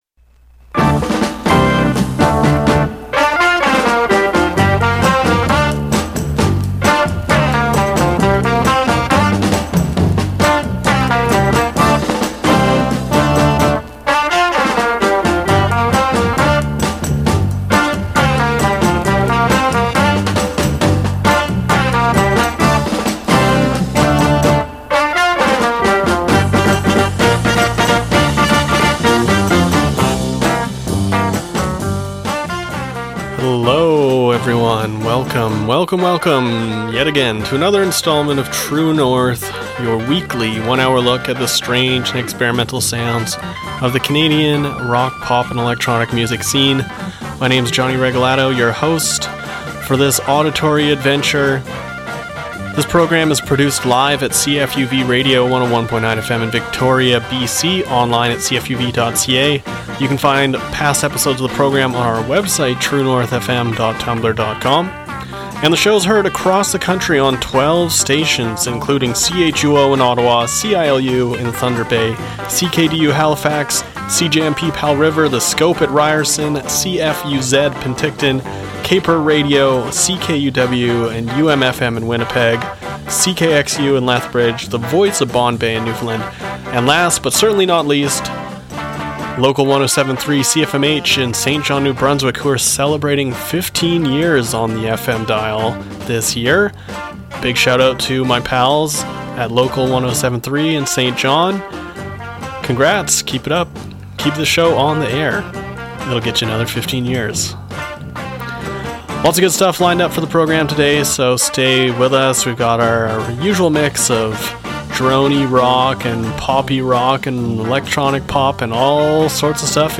An hour of strange, experimental and independent Canadian rock and pop